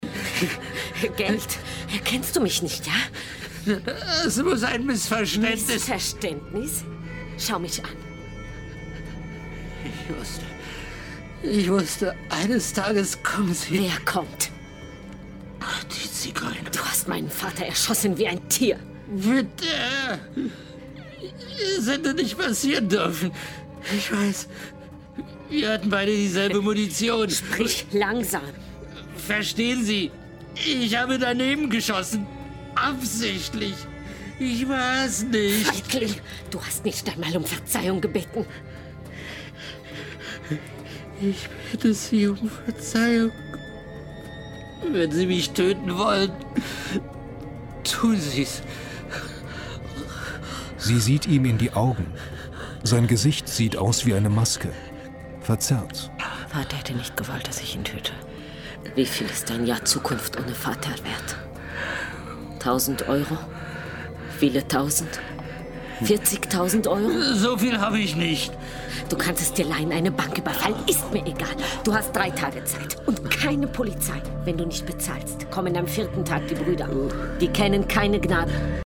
WDR Hörspiel